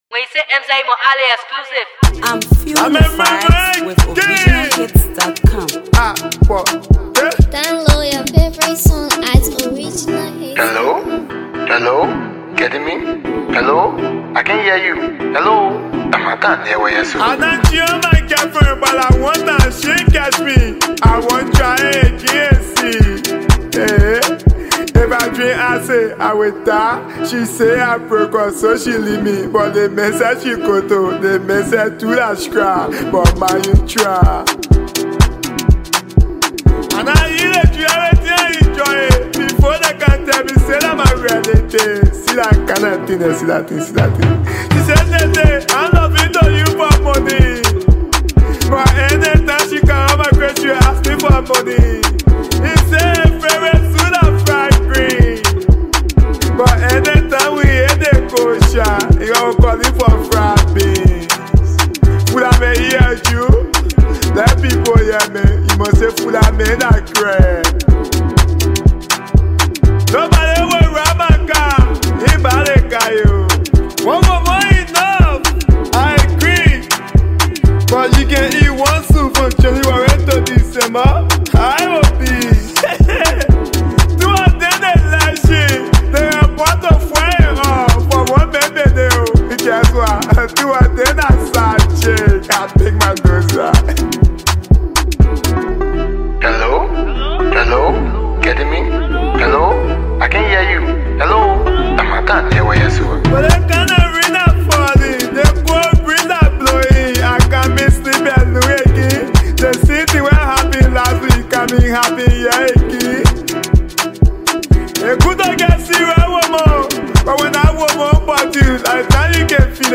it’s a certified banger for both street and club